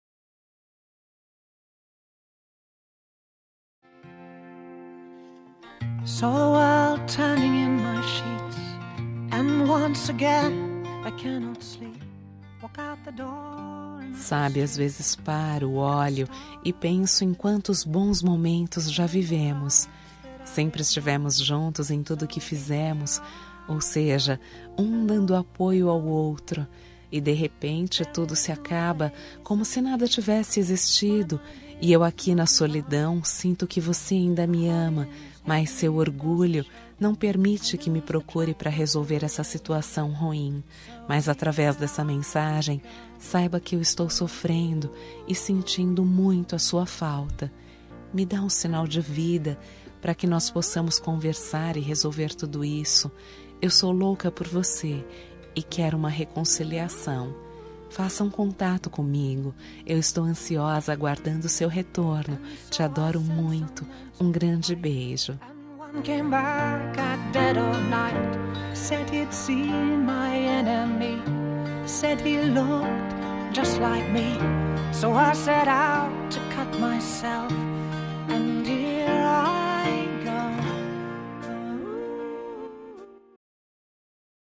Telemensagem de Reconciliação Romântica – Voz Feminina – Cód: 202086